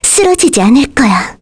Jane-Vox_Skill7-2_kr.wav